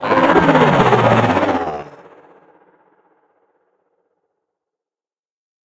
Sfx_creature_pinnacarid_callout_03.ogg